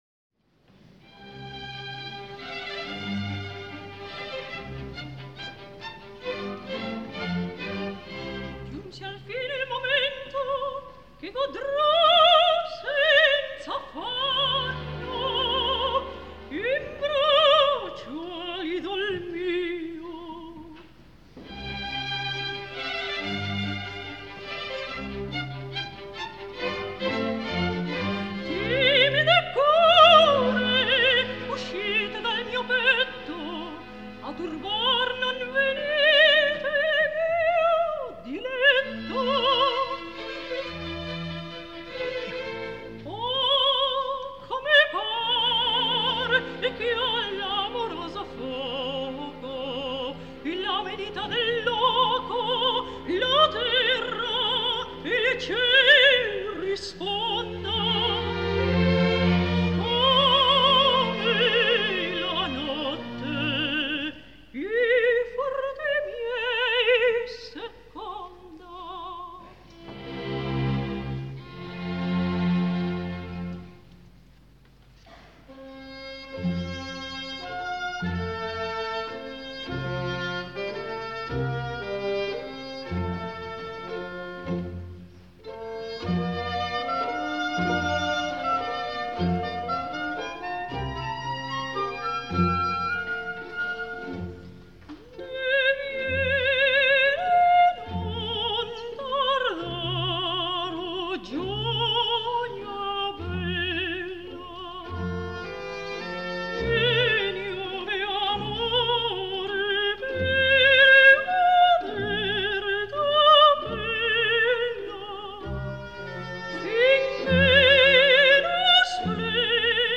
Consuelo Rubio, Soprano; OSR